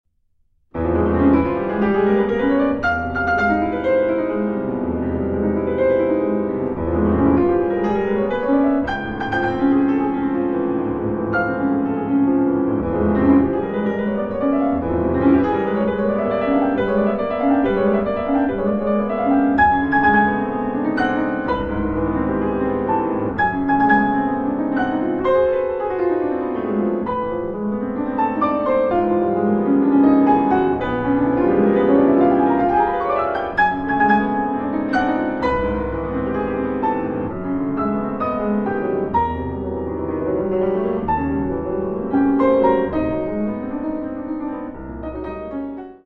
Tumultuous (3:32)